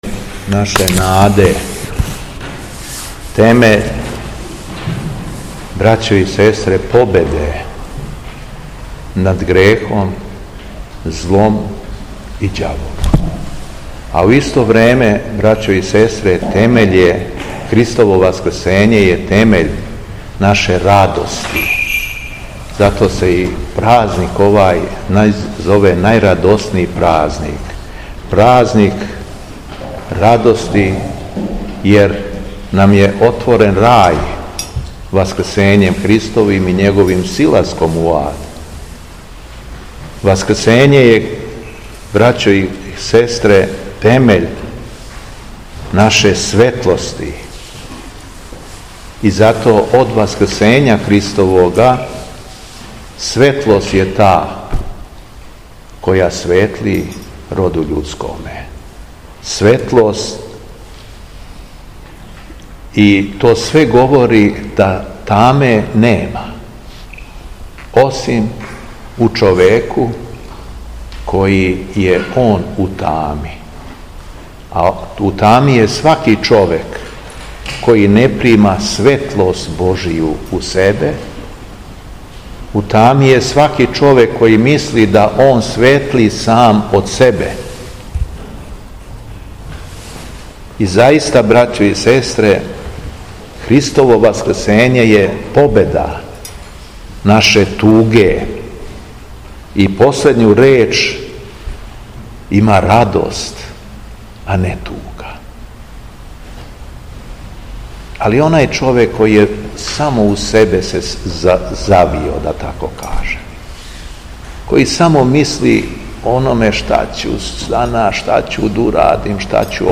Беседа Његовог Високопреосвештенства Митрополита шумадијског г. Јована
Беседећи верном народу, Митрополит Јован је рекао: